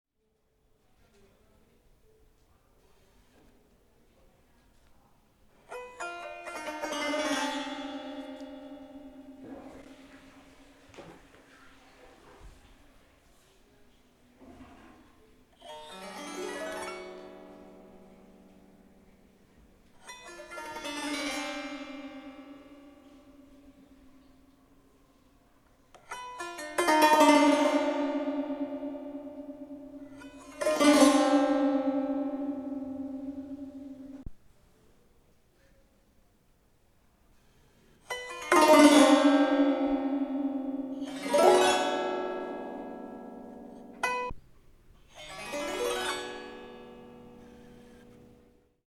listen to it here) of me trying out the 'dan tranh', a Vietnamese zither with (often) 16 strings, which can sound really fabulous. The sample sounds metallic to me, especially after I have been working with it.